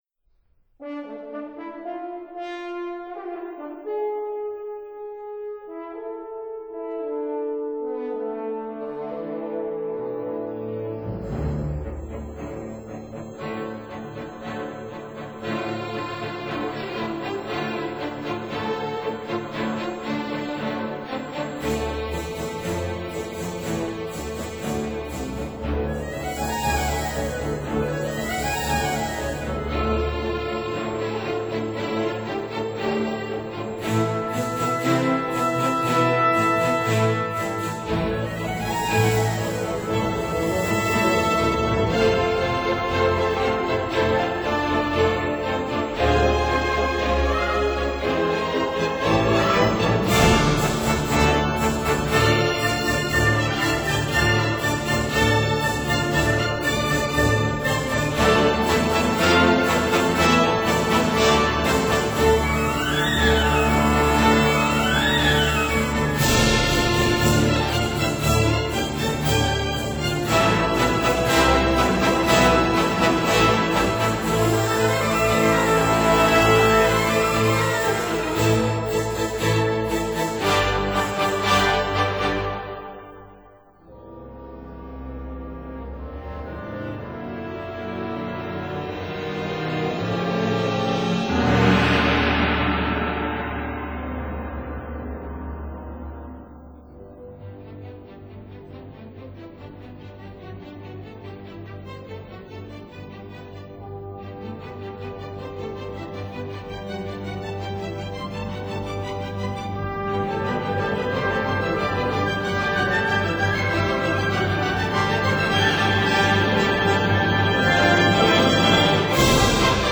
ballet Op. 37